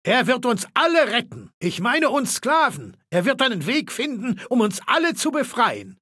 Maleold01_ms06_ms06hamlininfo_0005a20c.ogg (OGG-Mediendatei, Dateigröße: 53 KB.
Fallout 3: Audiodialoge